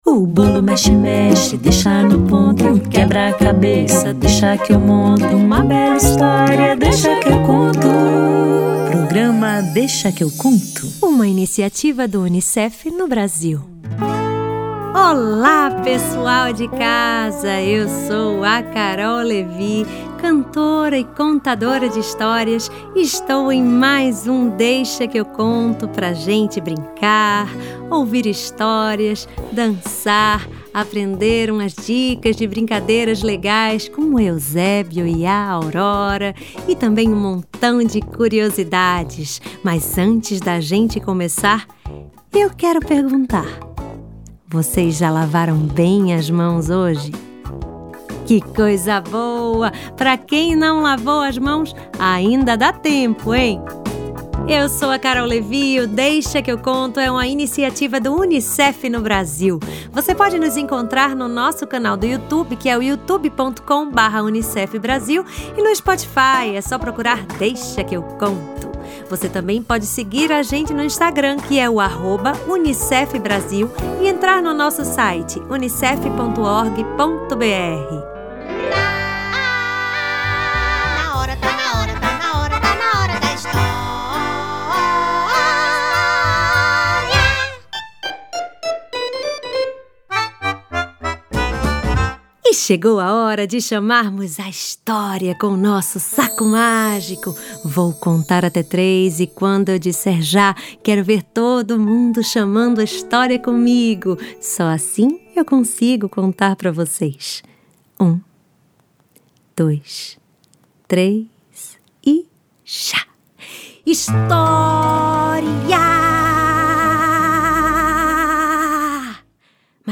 O Euzébio e a Aurora arrumaram o quarto e descobriram muitas coisas legais por isso. Um cachorro pulguento vai cantar.